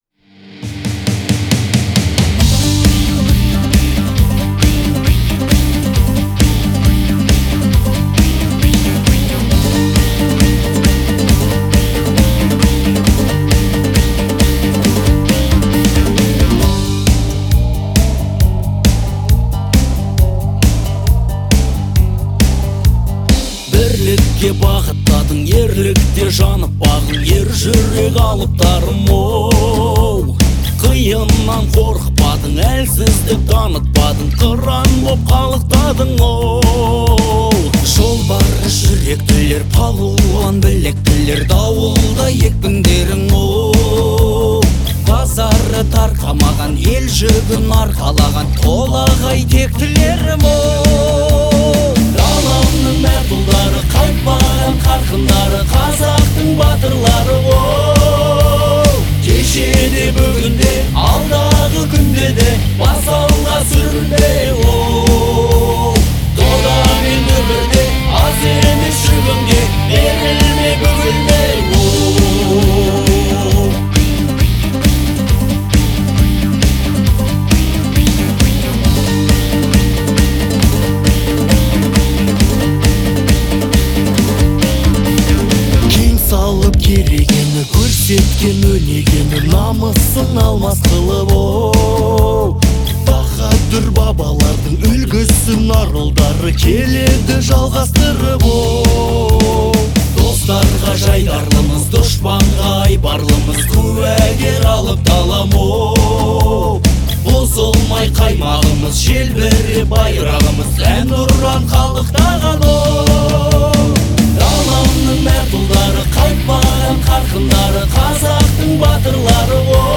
мощная и вдохновляющая композиция
выполненная в жанре патриотической музыки.